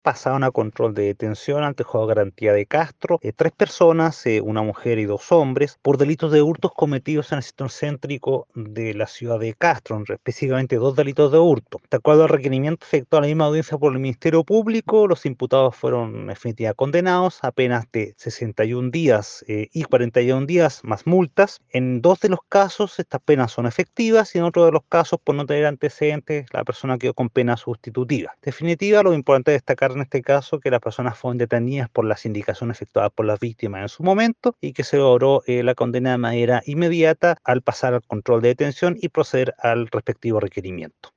Desde el Ministerio Público, el Fiscal Javier Calisto se refirió a las sanciones aplicadas a los imputados::